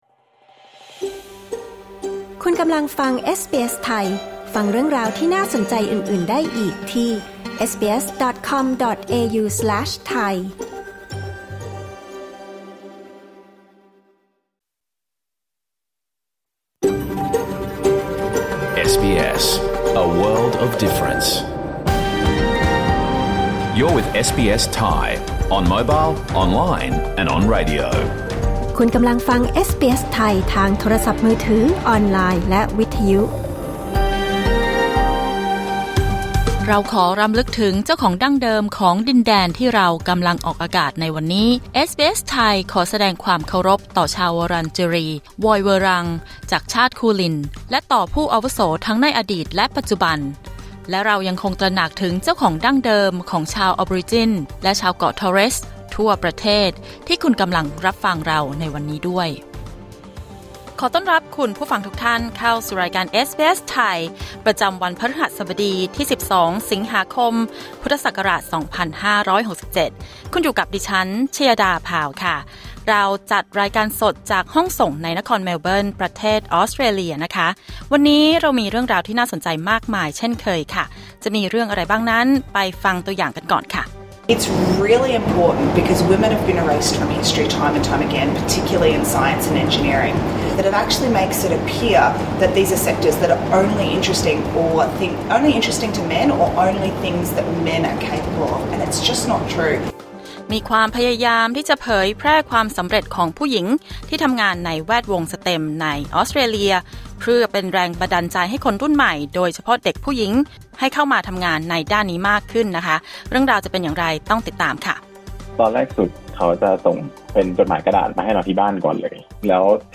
รายการสด 12 สิงหาคม 2567